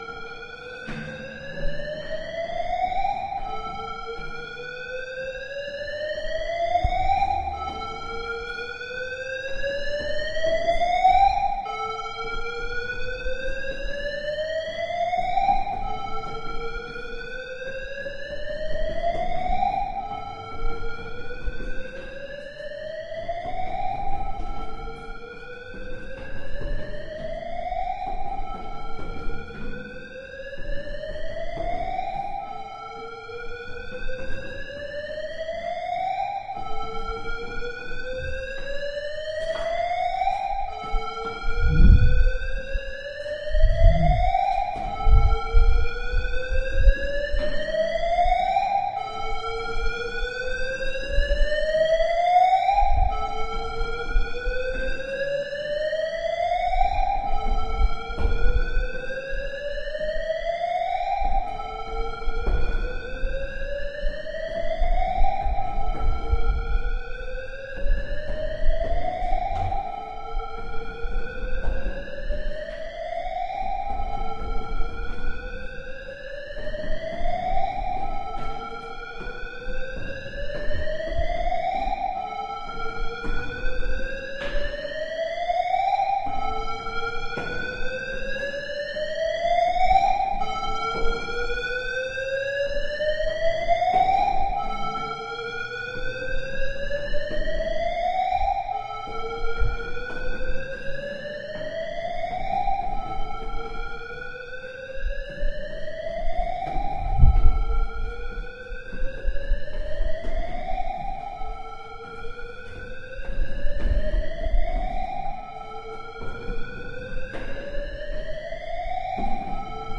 徒步旅行 " 走过树林的脚步声
麦克风由我自己掌控。你会听到脚步声，我的狗在附近乱窜。
Tag: DR05 格拉斯哥 脚步声 Tascam的 步骤 伍兹 Dawsholm 步行 Tascam的-DR05 公园 苏格兰 Dawsholm园 现场录音 行走